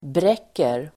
Uttal: [br'ek:er]